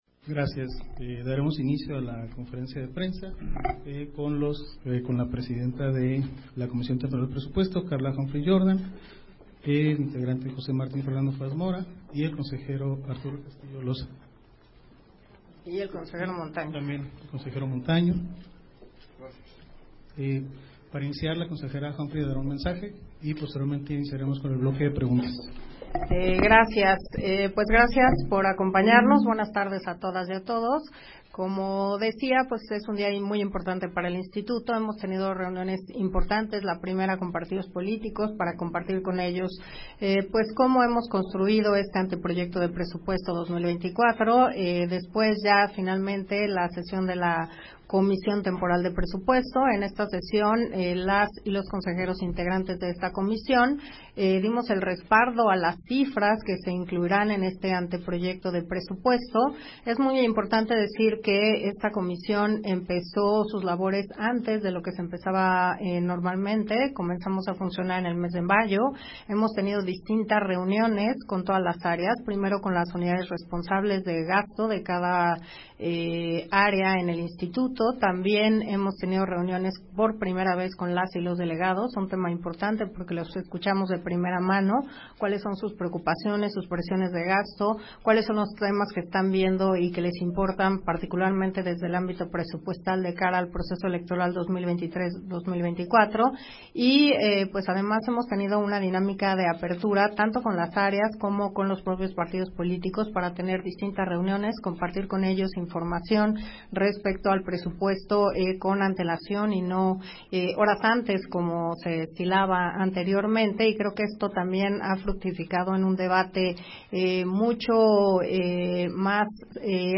140823_AUDIO_CONFERENCIA-DE-PRENSA